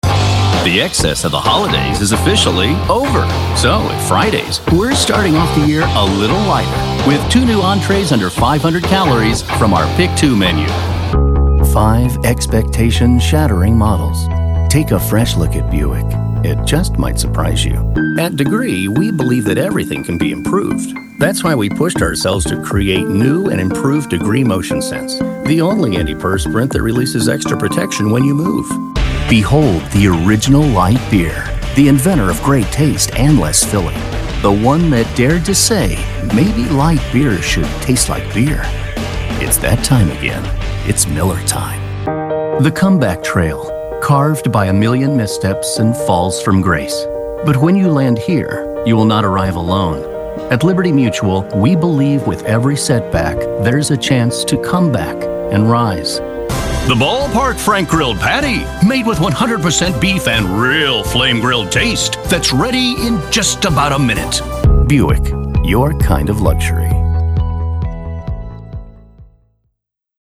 Warm tenor, sincere, ageless, real, natural, non-announcer voice actor.
middle west
Sprechprobe: Werbung (Muttersprache):